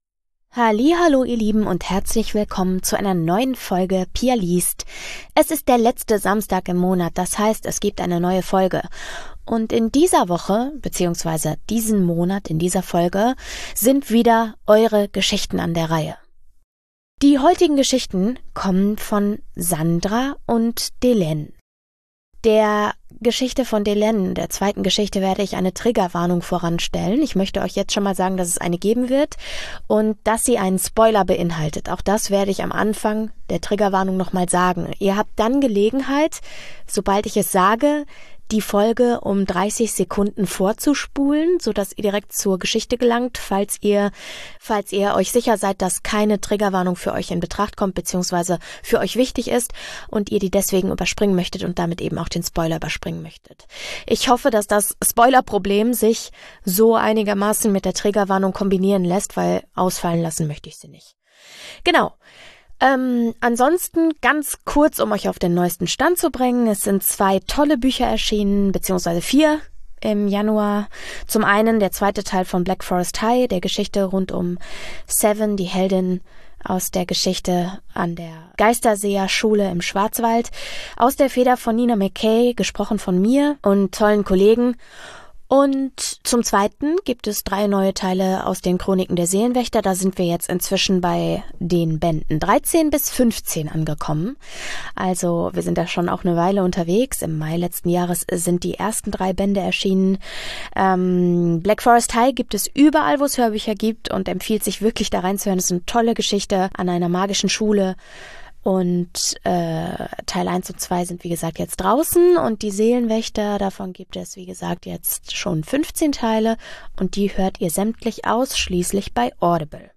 Jeden letzten Samstag im Monat gibt es dabei eine meist gruselige Geschichte, die ich inzwischen mit Geräuschen, Musik und Ambient-Sounds versehe, um euch